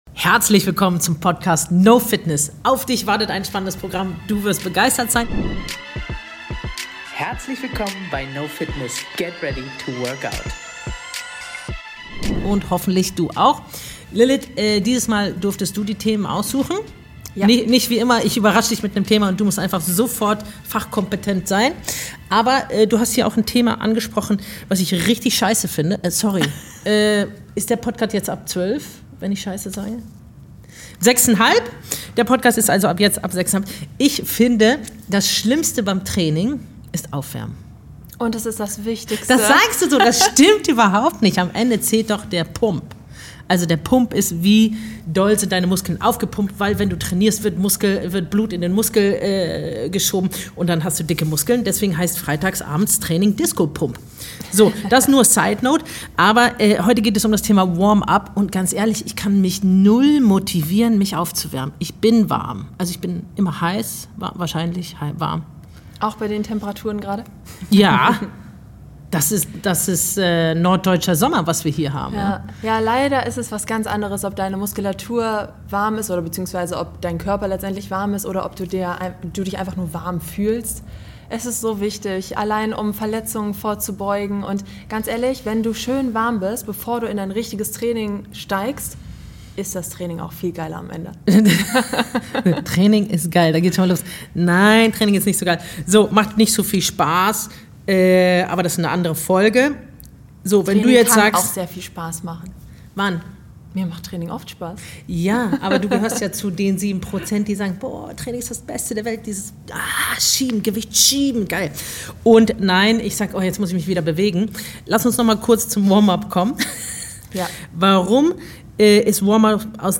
Gemeinsam beleuchten sie, wie ein effektives Warm-Up aussieht, warum es Verletzungen vorbeugt und wie du es mit Cardio oder Mobility smart in dein Training integrierst. Ein ehrlicher, unterhaltsamer Talk über den oft ungeliebten, aber entscheidenden Start ins Workout.